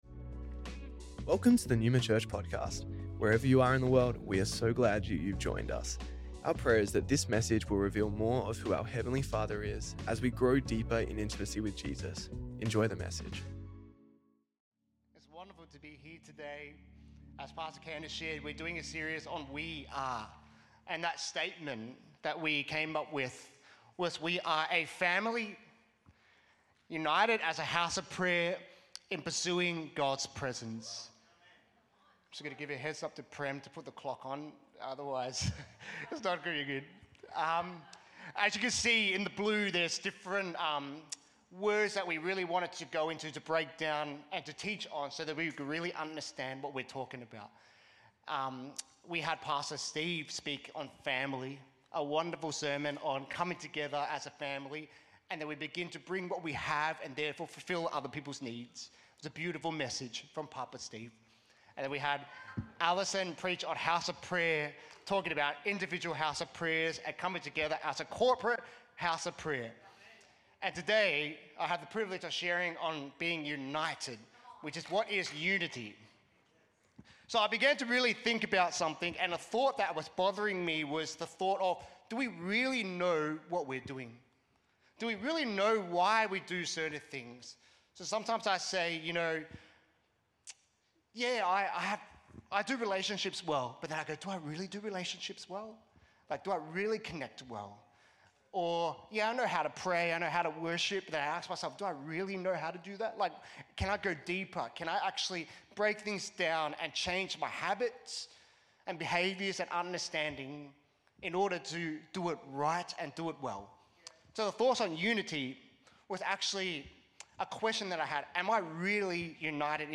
Neuma Church Melbourne South Originally Recorded at the 10AM service on Sunday 16th March 2025